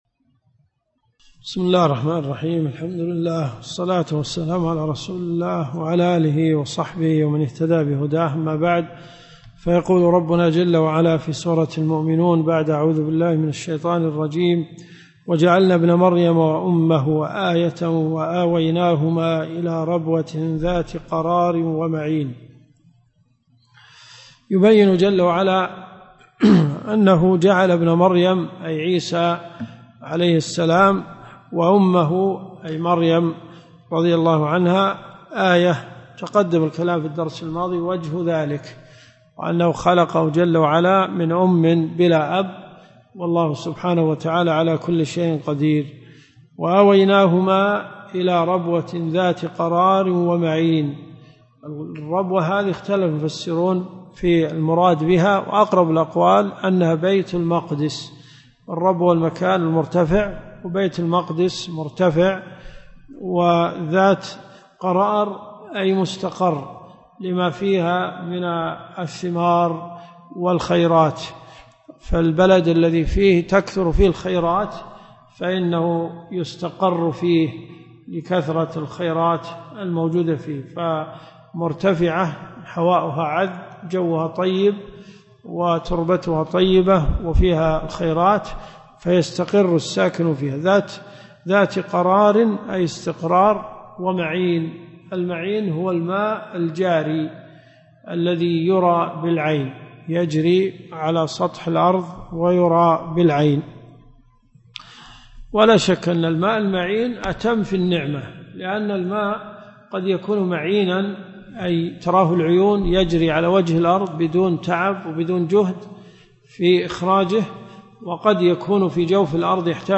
تفسير القران . سورة المؤمنون . من آية 5 -إلى- آية 89 . المدينة المنورة . جامع البلوي